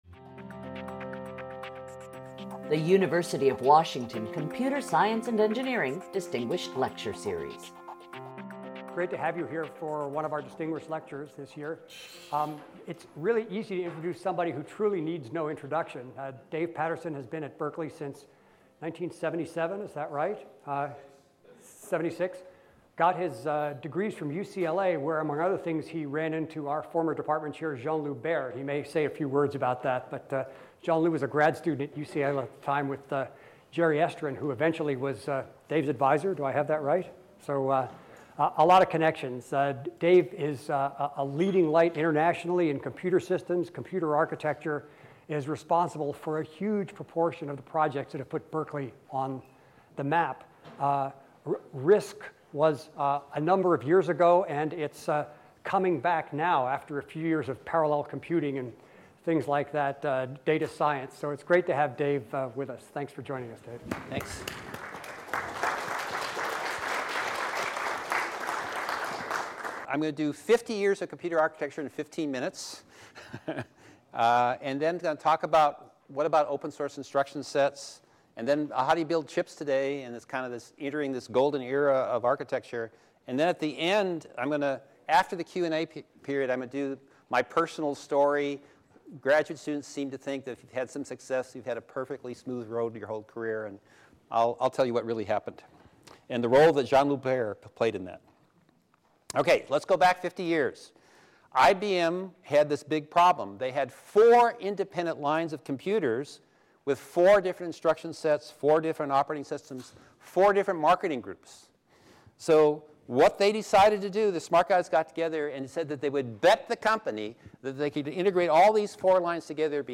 CSE Distinguished Lecture Series